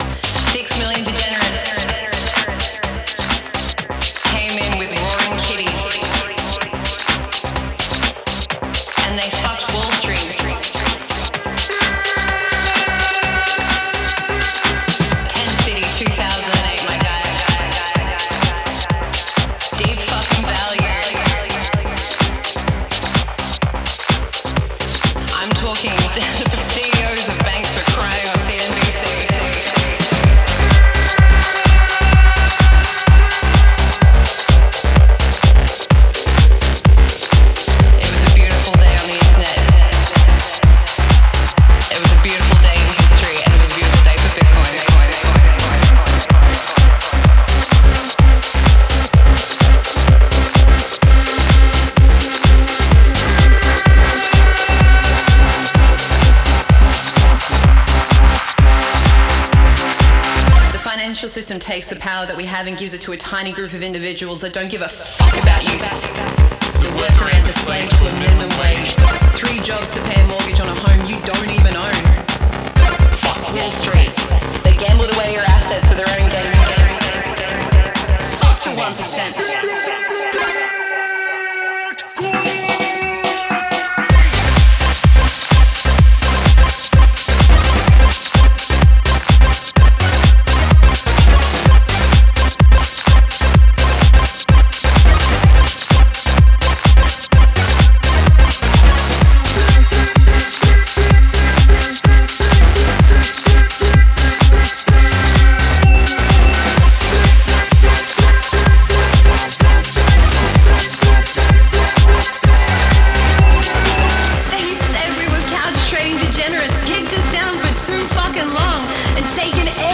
is a Bitcoin protest house banger.
vocals